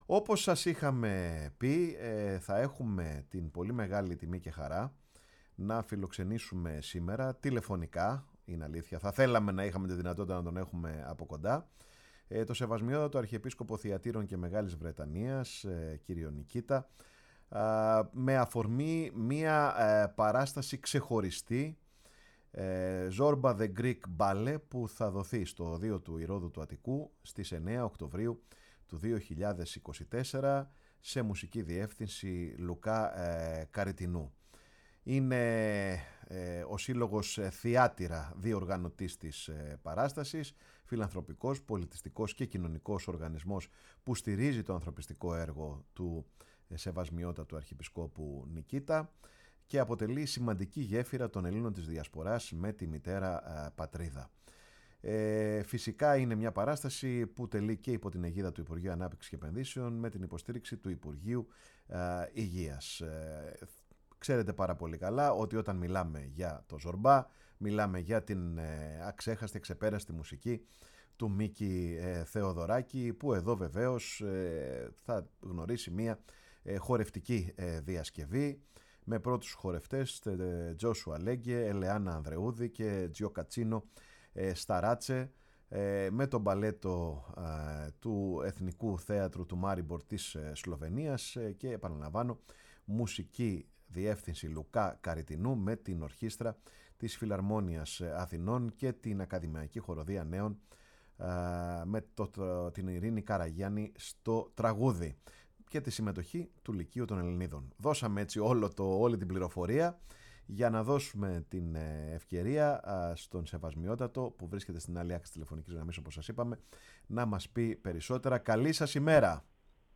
Τον Αρχιεπίσκοπο Θυατείρων και Μεγάλης Βρετανίας κ. Νικήτα φιλοξένησε σήμερα με αφορμή την παράσταση του παγκοσμίου φήμης έργου ”ZORBA THE GREEK”, η εκπομπή ”Πάρε τον Χρόνο σου”